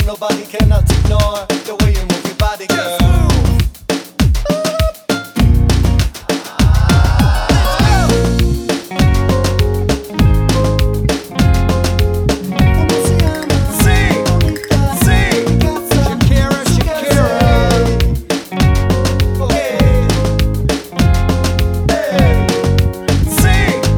for duet Pop (2000s) 3:40 Buy £1.50